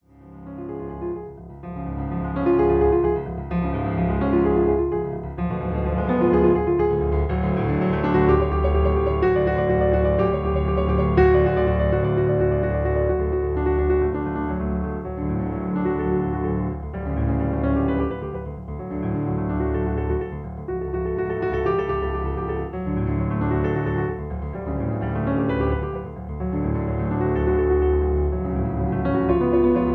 In D flat. Piano Accompaniment